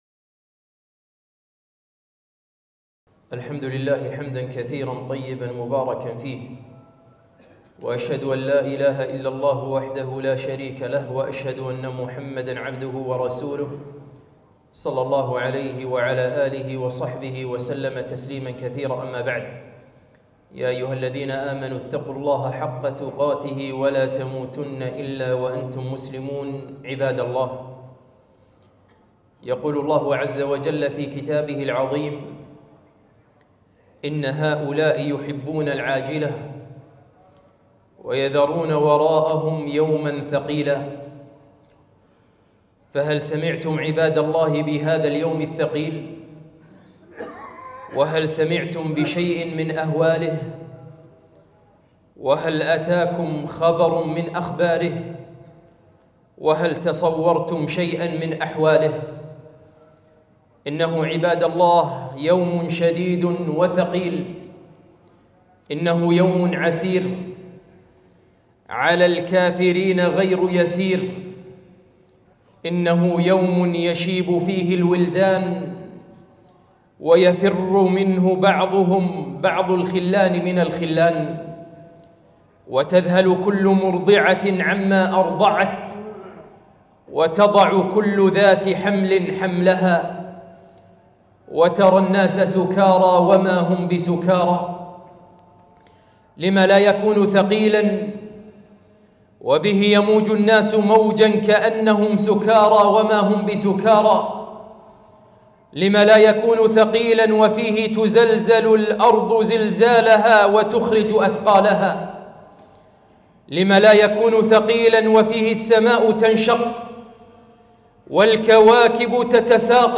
خطبة - اليوم الثقيل